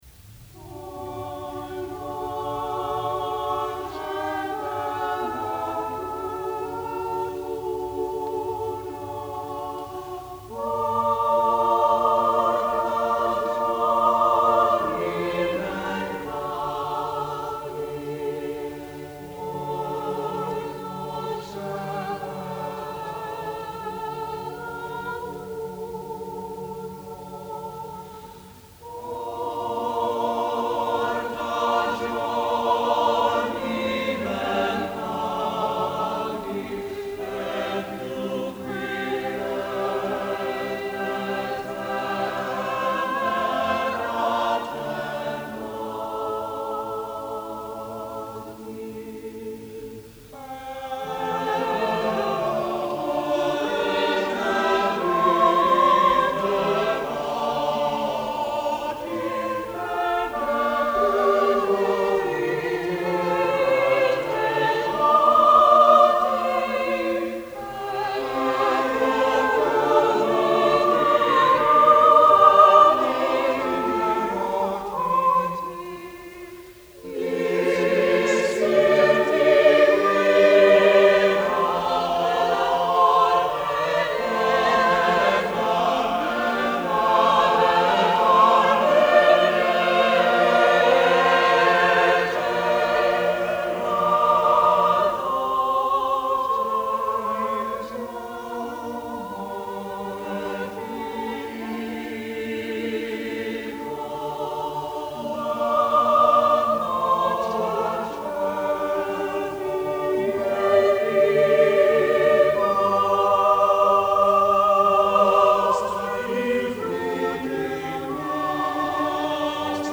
Baccusi, a prolific composer of madrigals (7 published books), masses, motets, psalm settings, the equal of madrigalists Marenzio, de Wert, or de Monte in contrapuntal skill, yet he is accorded even less recognition than they in studies of the period.
. 21 in all and all for 5 voices.